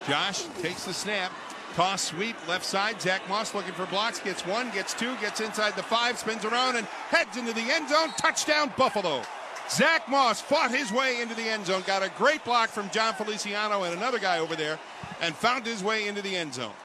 PBP Bills 28-0-Moss 7-Yd TD Run
PBP-Bills-28-0-Moss-7-Yd-TD-Run.mp3